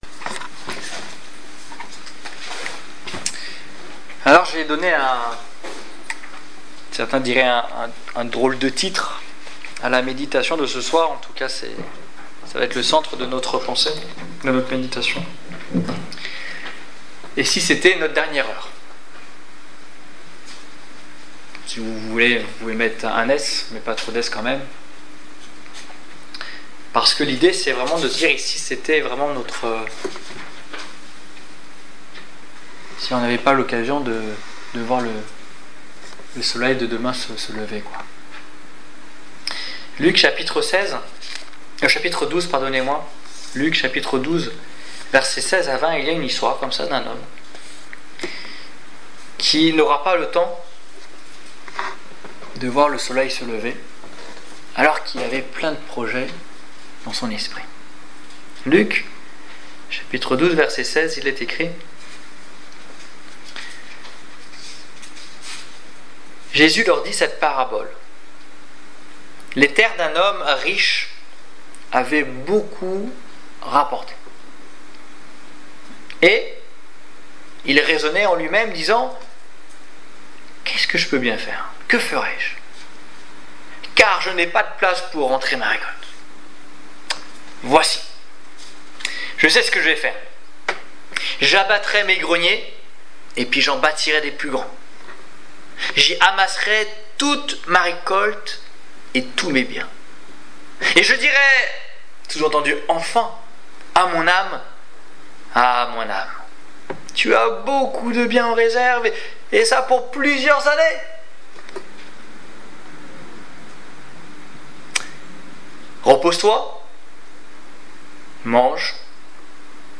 Annonce de l'évangile du 9 septembre 2016